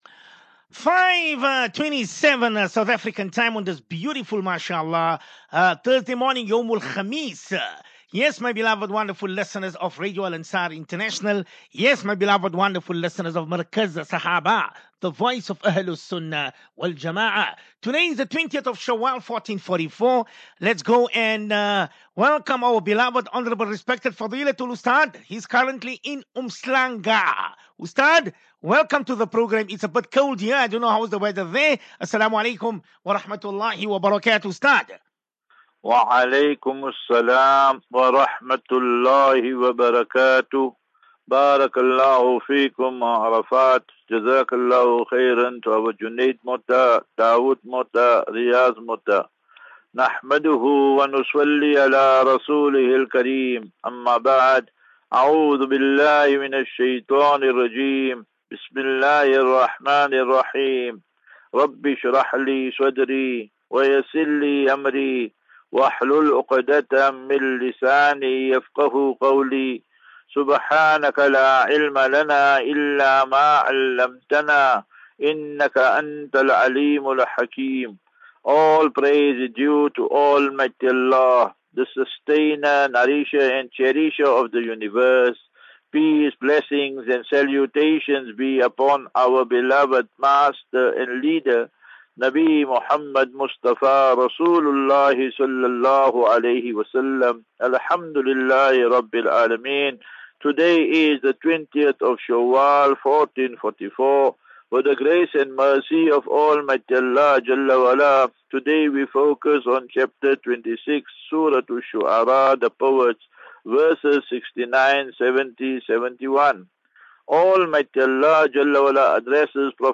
As Safinatu Ilal Jannah Naseeha and Q and A 11 May 11 May 23 Assafinatu